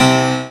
55y-pno13-g4.aif